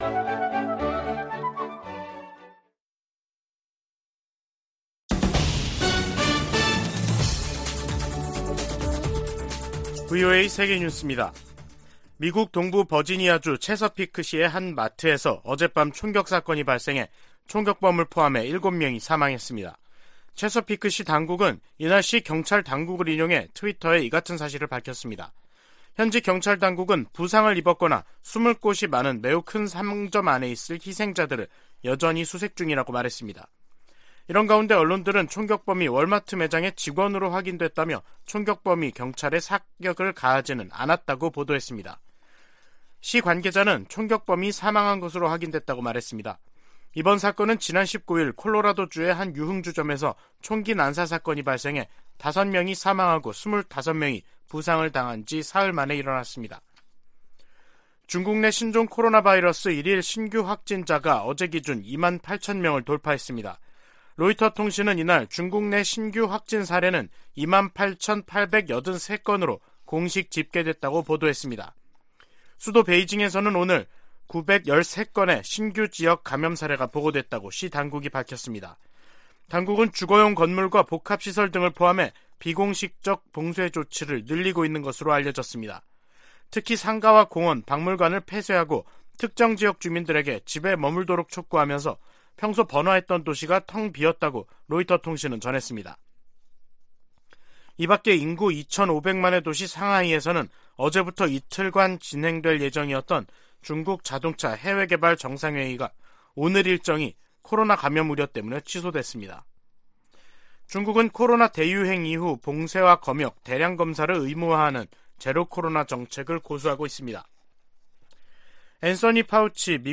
VOA 한국어 간판 뉴스 프로그램 '뉴스 투데이', 2022년 11월 23일 2부 방송입니다. 백악관의 존 커비 전략소통조정관은 중국이 북한에 도발적인 행동을 멈추도록 할 수 있는 압박을 가하지 않고 있다고 지적했습니다. 중국이 북한 불법무기 프로그램 관련 유엔 안보리 결의를 전면 이행해야 한다고 미 국방장관이 촉구했습니다.